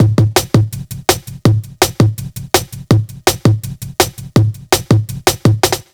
Index of /90_sSampleCDs/USB Soundscan vol.05 - Explosive Jungle [AKAI] 1CD/Partition C/08-165BREAK4